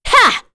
Demia-Vox_Attack7.wav